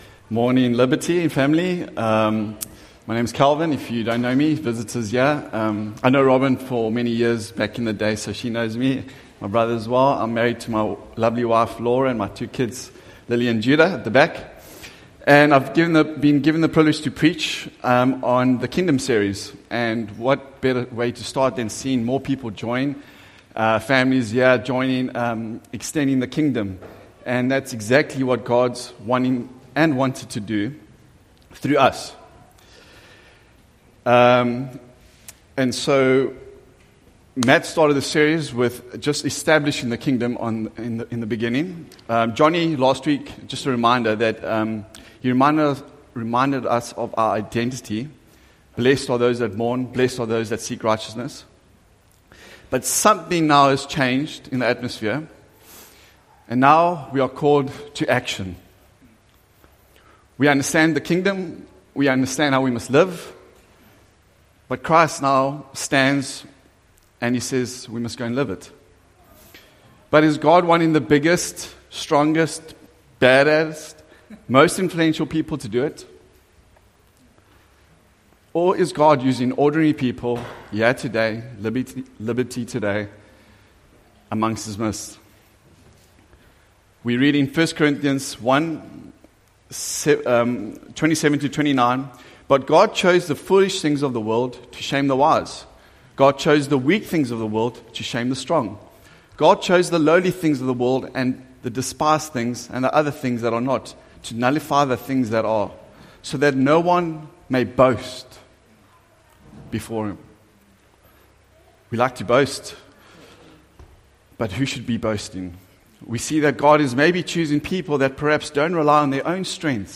SERMON: Kindom Influence – Salt and Light